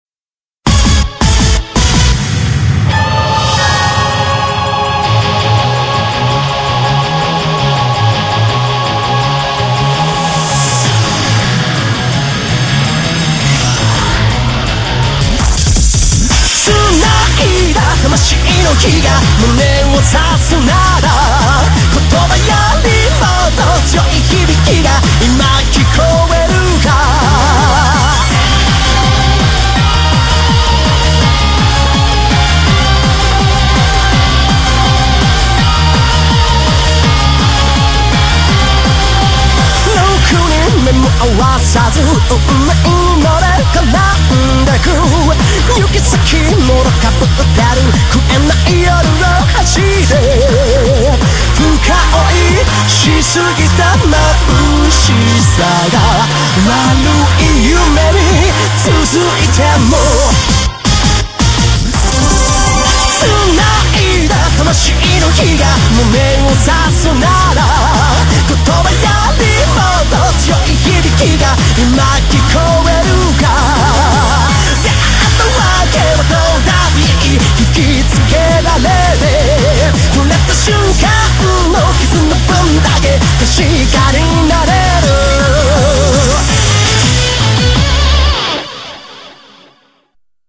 BPM165
Audio QualityPerfect (High Quality)
... Actually, the audio quality kinda sucks here.